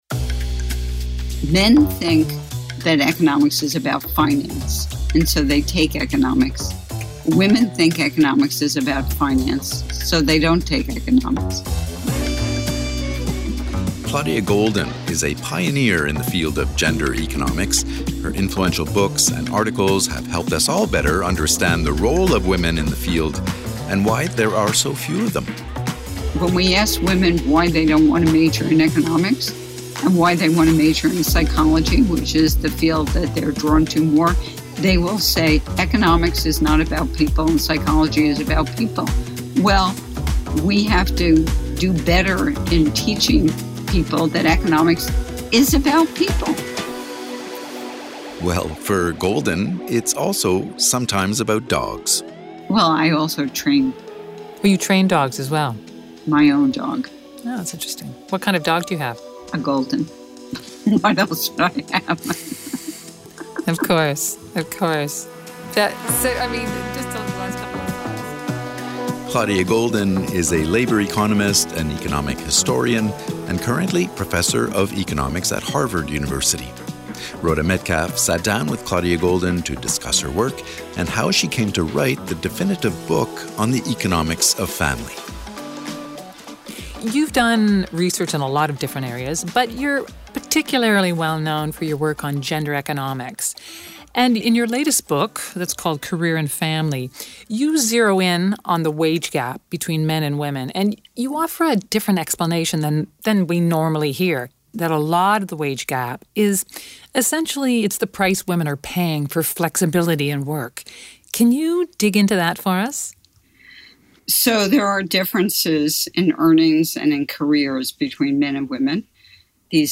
The interview is part of the IMF series on extraordinary Women in Economics.